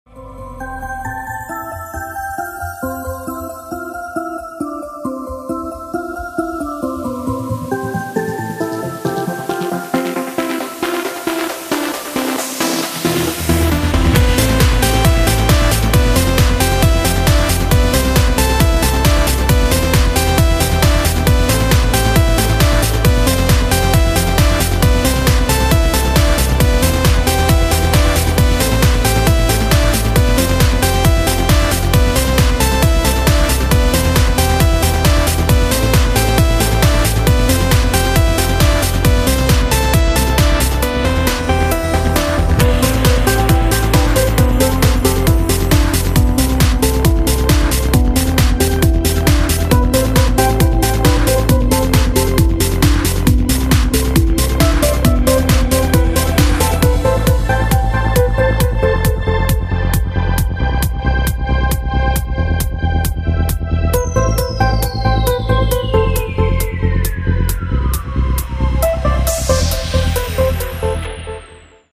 • Качество: 128, Stereo
громкие
dance
Electronic
EDM
электронная музыка
без слов
club
progressive trance